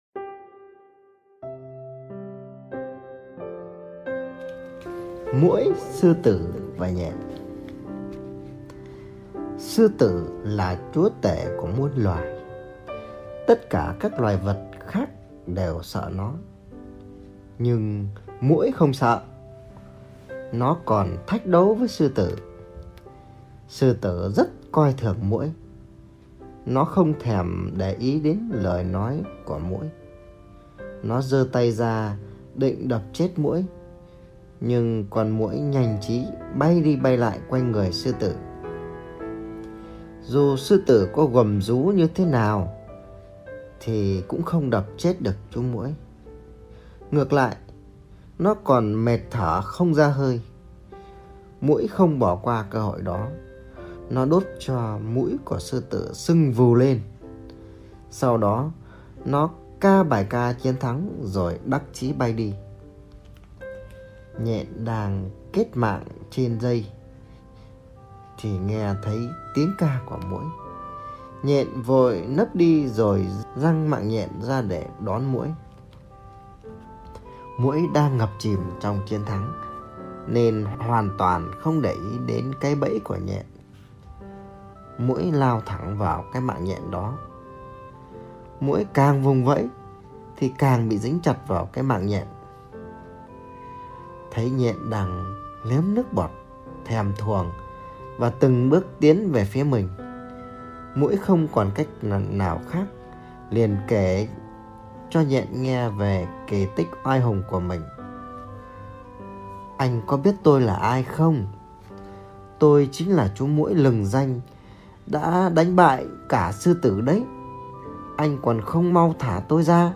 Sách nói | Sư tử và muỗi